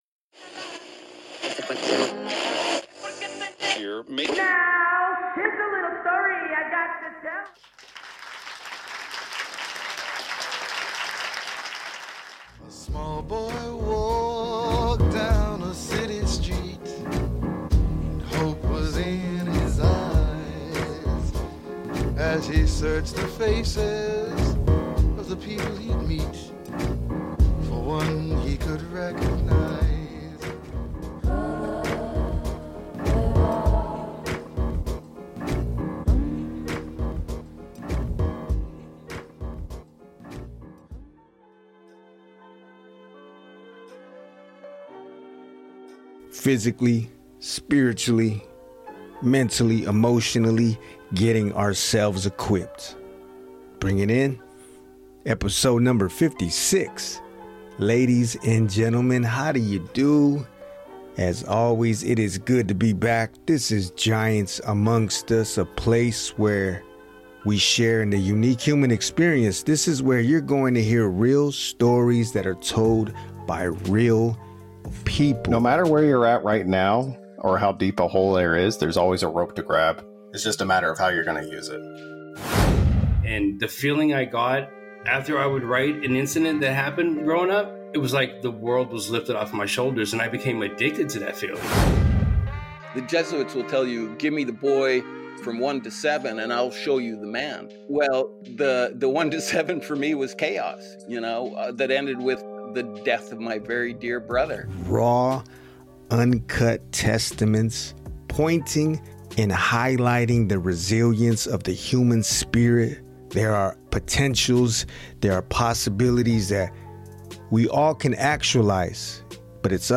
in-depth conversation